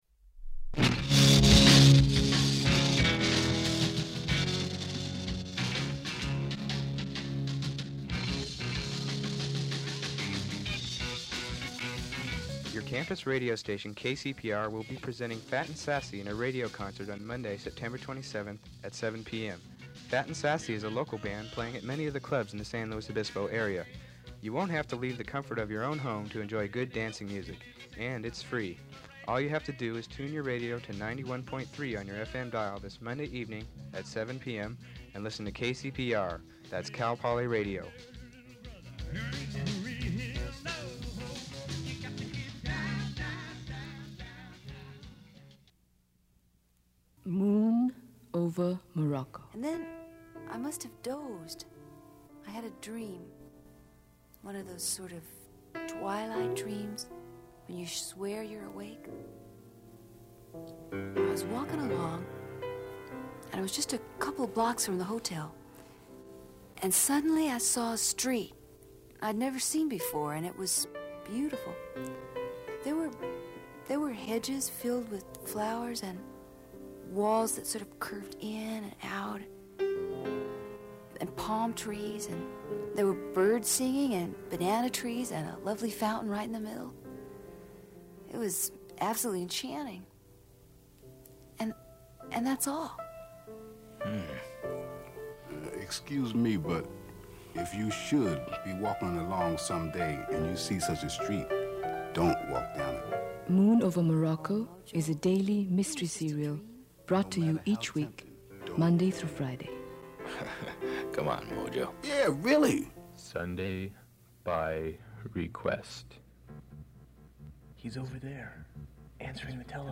Promotional Spots '76-'77